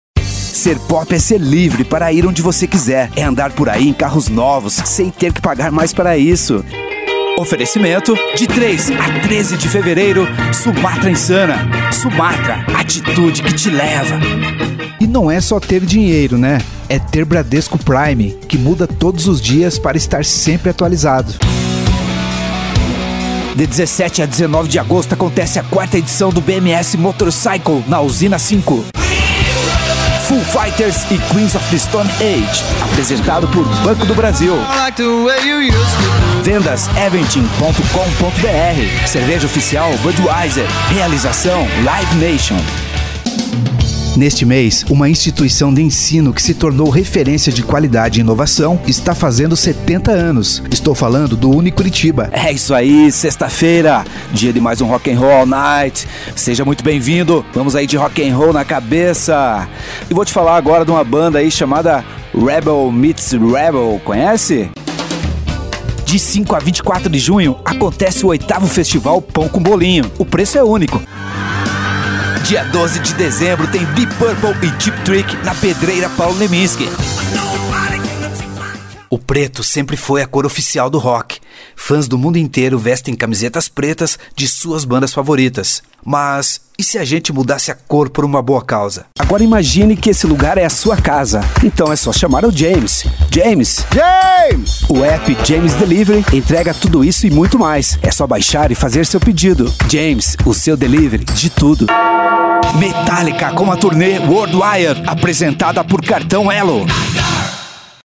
VOZES MASCULINAS
Estilos: Padrão Dialogo